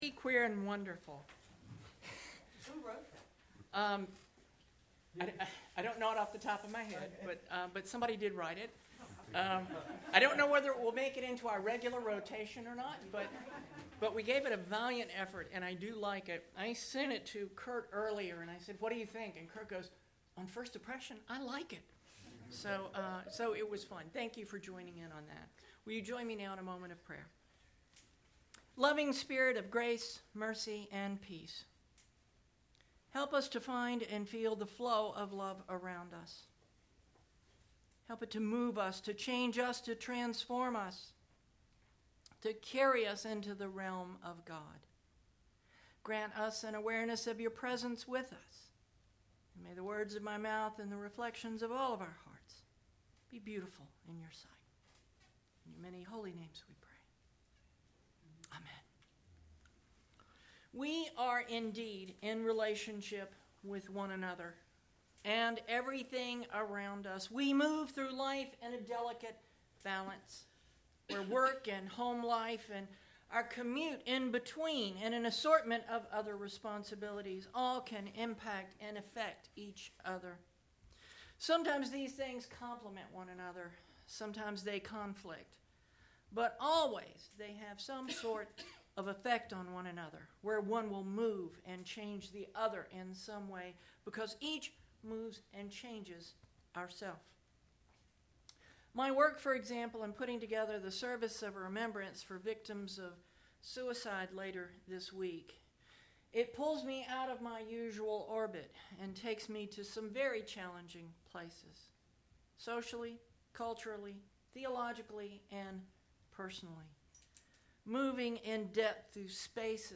9/24 Sermon Posted